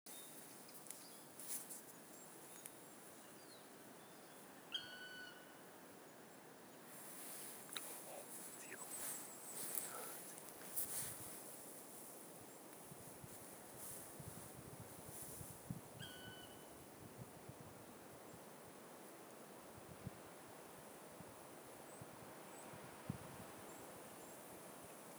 Birds -> Woodpeckers ->
Black Woodpecker, Dryocopus martius
StatusSinging male in breeding season